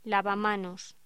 Locución: Lavamanos
voz